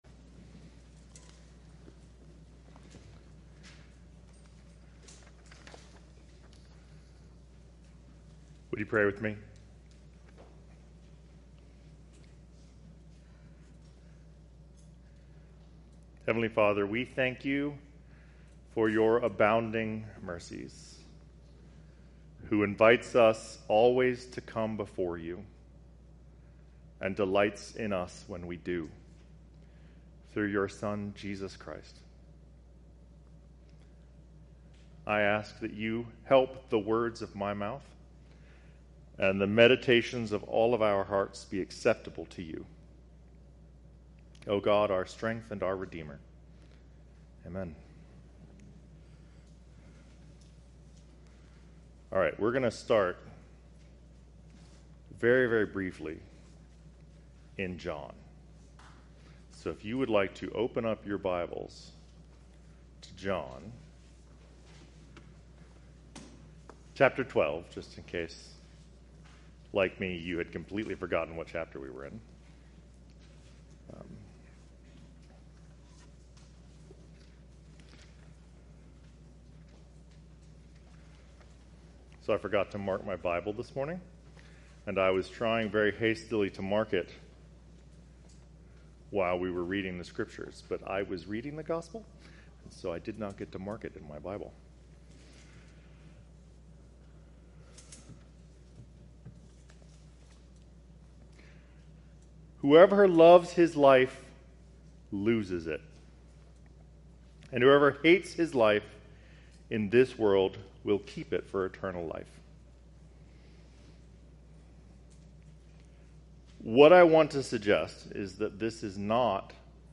Life, According to God - St. Andrew's Anglican Church